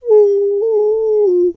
wolf_2_howl_soft_04.wav